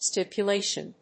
音節stip・u・la・tion 発音記号・読み方
/stìpjʊléɪʃən(米国英語), ˌstɪpjʌˈleɪʃʌn(英国英語)/